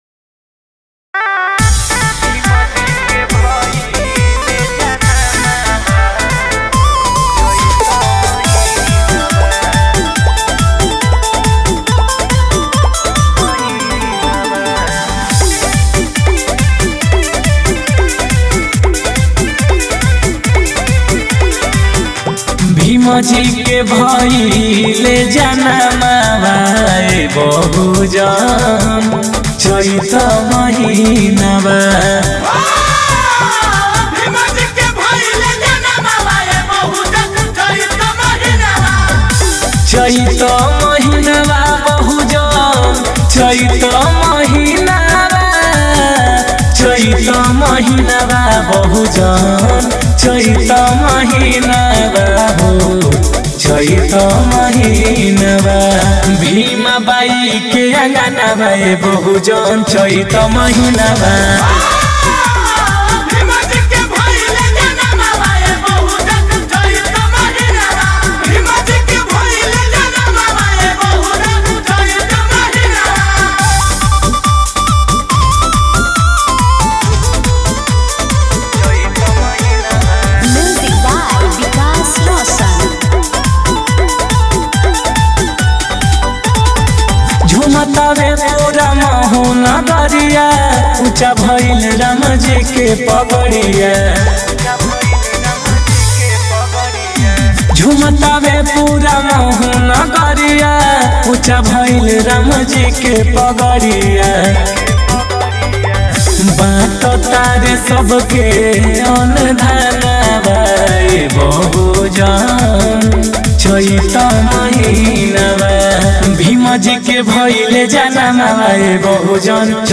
Releted Files Of Bhojpuri Mp3 Song